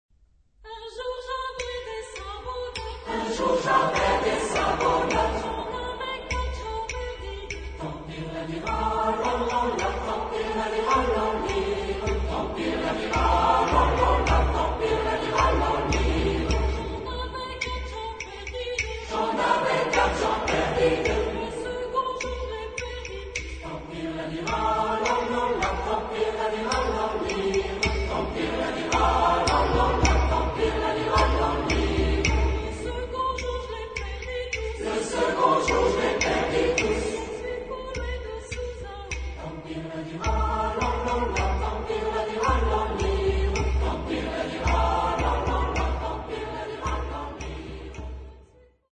Genre-Style-Form: Popular ; Traditional ; Secular ; Dance
Type of Choir: SATB  (4 mixed voices )
Tonality: F major